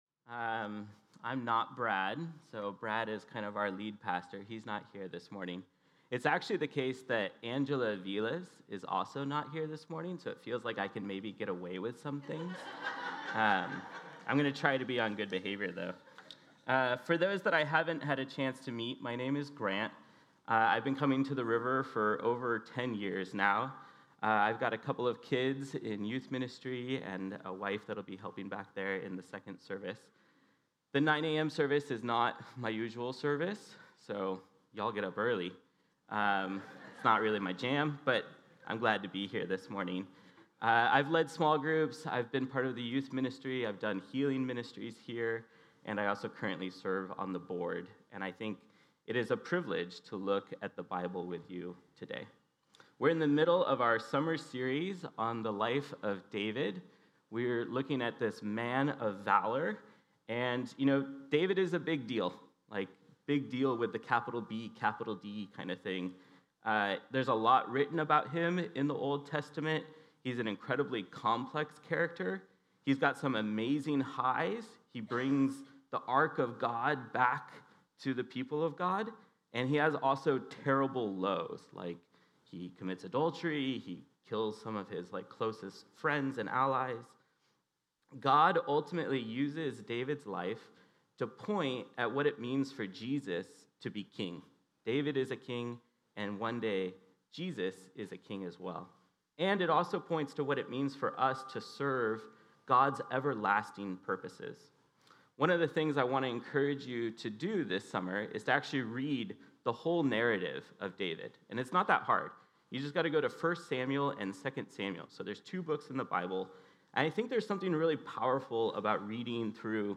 The River Church Community Sermons David